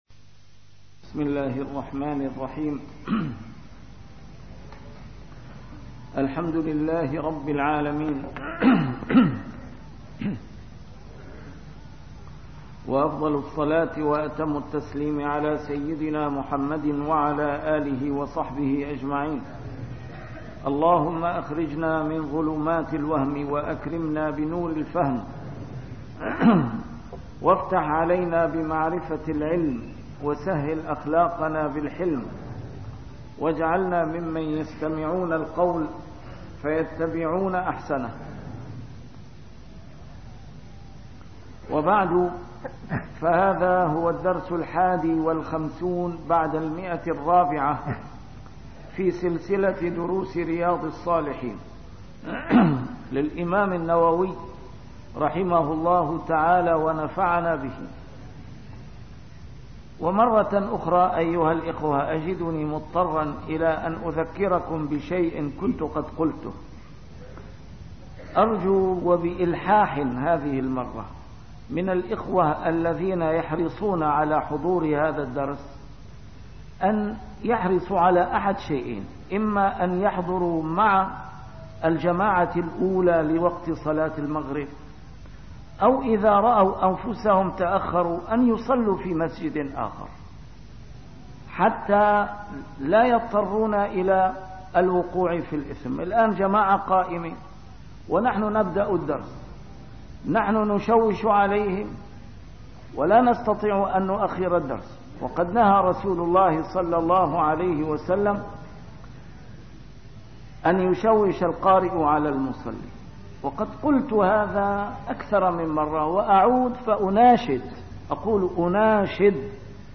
A MARTYR SCHOLAR: IMAM MUHAMMAD SAEED RAMADAN AL-BOUTI - الدروس العلمية - شرح كتاب رياض الصالحين - 451- شرح رياض الصالحين: علامات حب الله للعبد